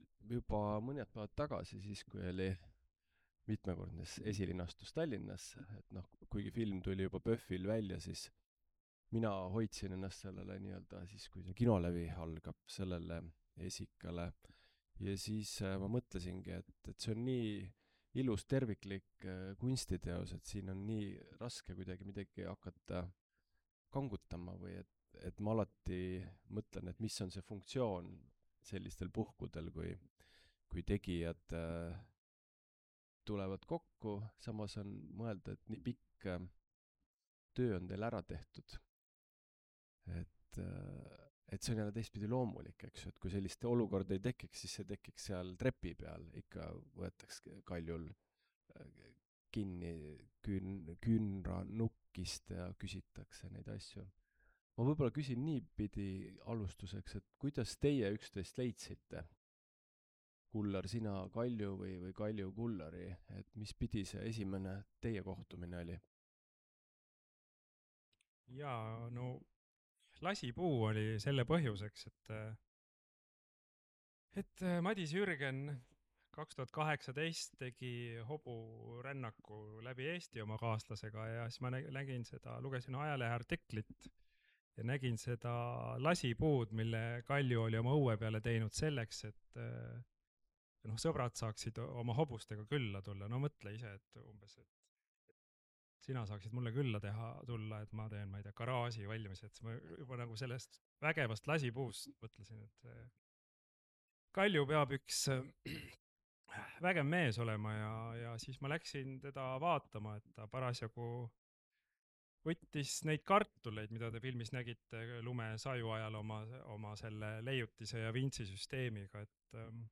NB! Vestluse salvestus sisaldab sisurikkujaid.